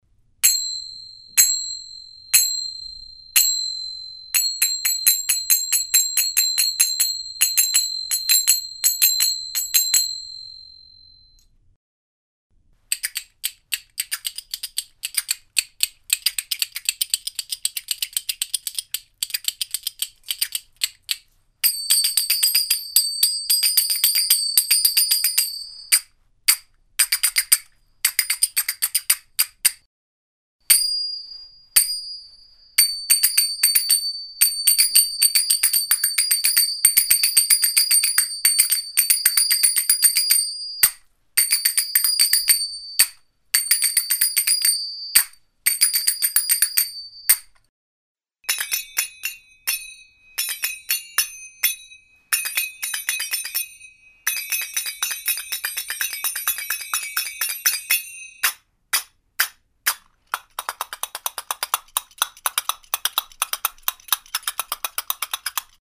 Egyptian Finger Cymbals - Sagat
This is an mp3 file that has 1 clappers 2 awlad 3 almee 4 zar.
Clappers - Brass castanets - $50.00
Almee - Traditional bell sagats - $45.00
Each gives a different tone.
sagat clappers awlad almee zar.mp3